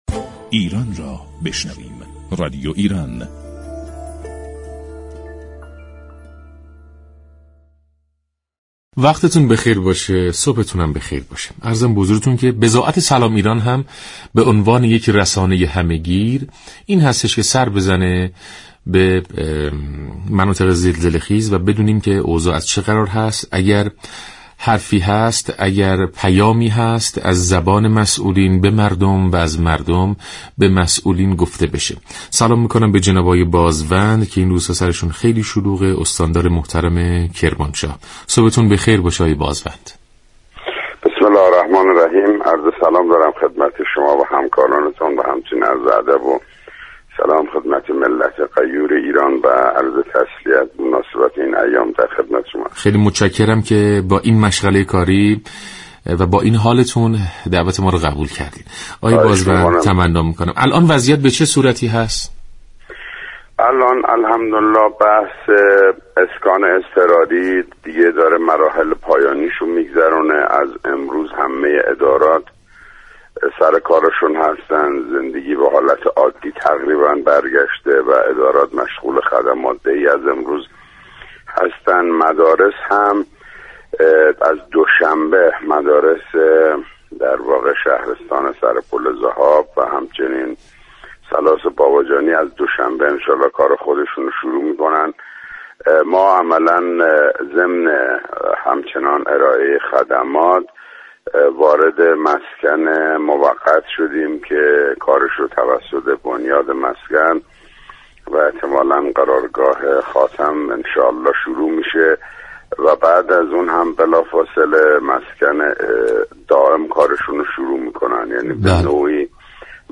استاندار كرمانشاه درگفت و گو با رادیو ایران گفت: چادرها به مقدار نیاز میان مردم توزیع شده است؛ روستاهایی كه امكان دسترسی به آنها وجود ندارد از طریق بالگرد، چادر و دیگر نیازهای ضروری را در اختیارشان قرار دادیم